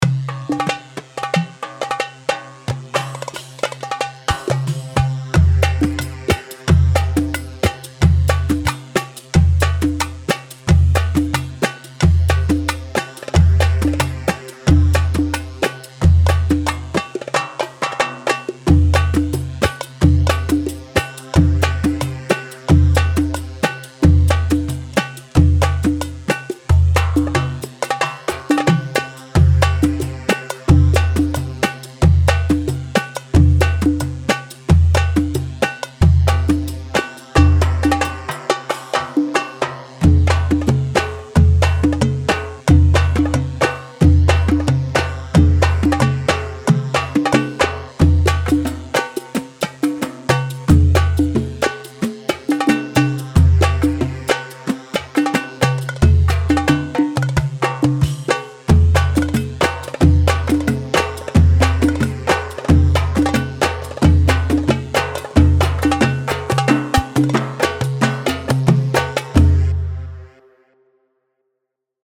Hewa 4/4 90 هيوا